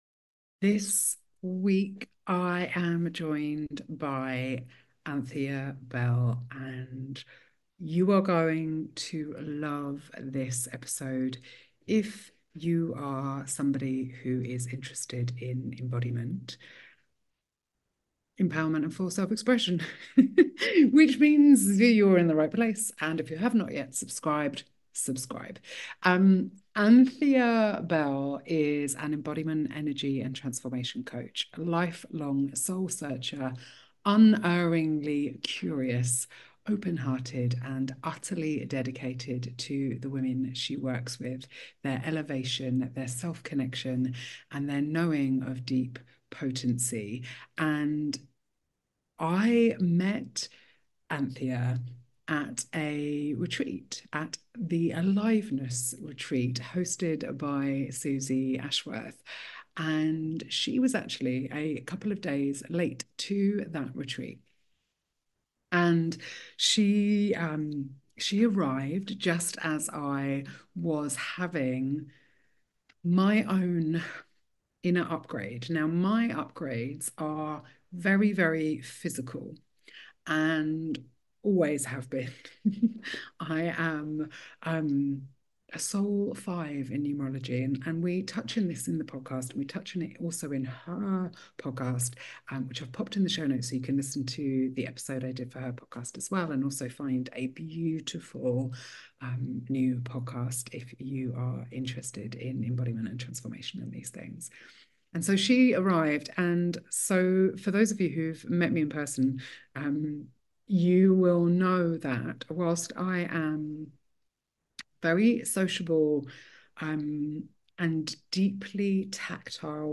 Each week I have real and raw conversations about what it takes to be fully resourced and have happy healthy intimate relationships as a dopamine-deficient adult wanting to create a better future by being the change you want to see in the world.